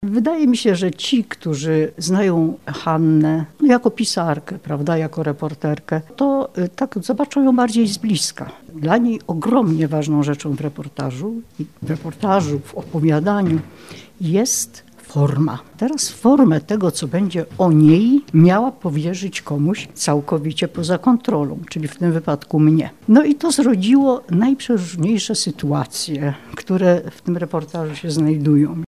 Rozmowa o niezwykłym reportażu [ZDJĘCIA]